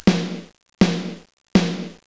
Snaredrum
SnareDrum.wav